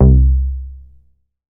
MoogCar 003.WAV